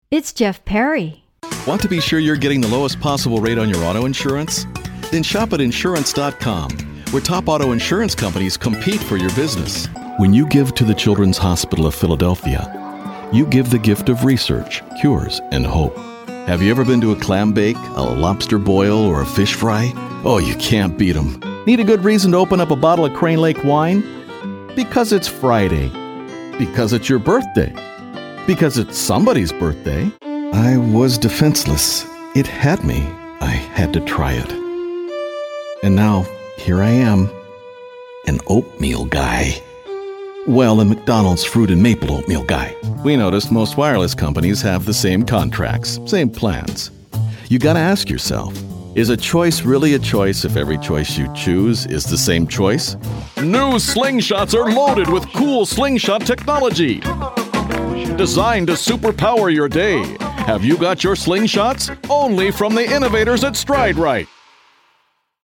Warm and Friendly
Kein Dialekt
Sprechprobe: Werbung (Muttersprache):